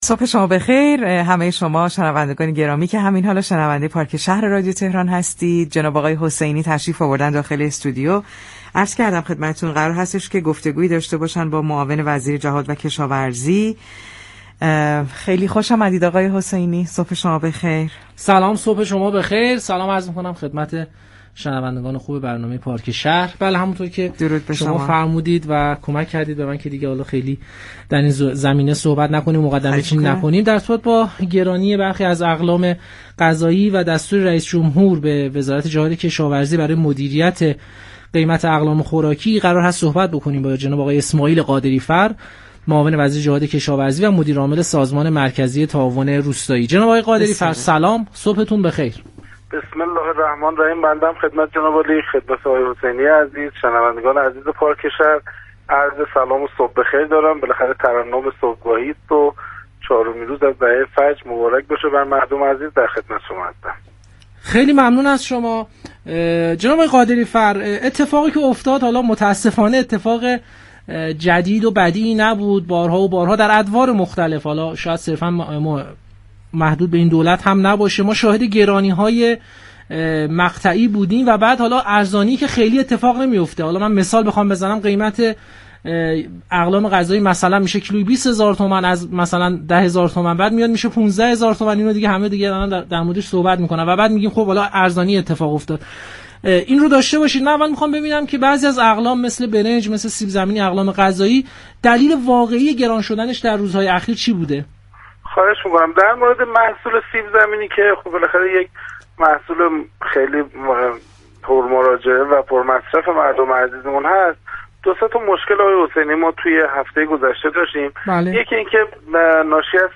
به گزارش پایگاه اطلاع رسانی رادیو تهران، اسماعیل قادری‌فر معاون وزیر جهاد كشاورزی و مدیرعامل سازمان مركزی تعاون روستایی در گفتگو با پارك شهر رادیو تهران در خصوص گرانی برخی از اقلام غذایی ازجمله سیب‌زمینی و برنج در روزهای اخیر گفت: هجوم كسبه‌ و اتحادیه‌ها برای صادرات سیب‌زمینی و سیلی كه اخیرا در مناطق جنوبی كشور رخ داد باعث گرانی سیب‌زمینی شده است.